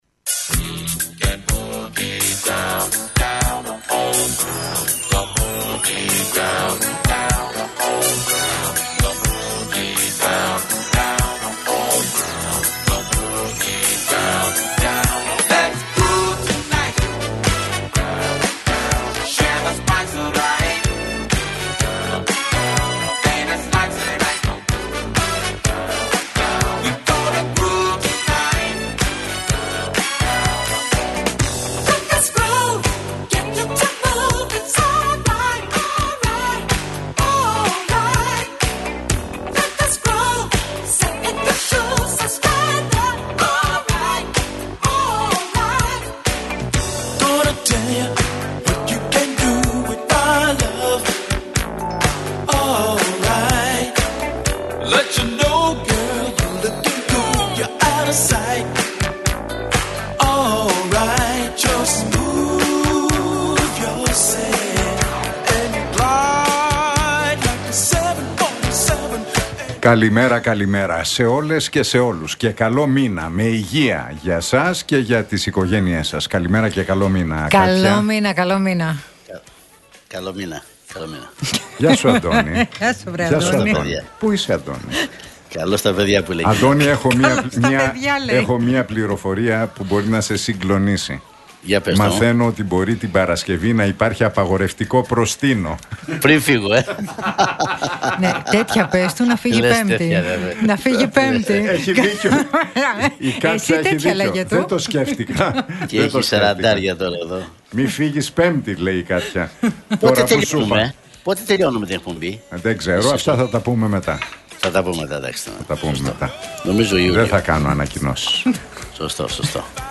Ακούστε την εκπομπή του Νίκου Χατζηνικολάου στον ραδιοφωνικό σταθμό RealFm 97,8, την Τρίτη 1 Ιουλίου 2025.